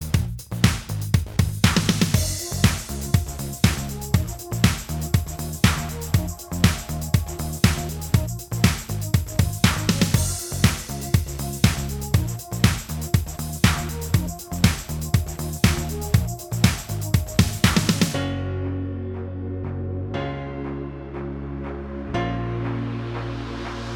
Minus Main Guitars Pop (1990s) 3:50 Buy £1.50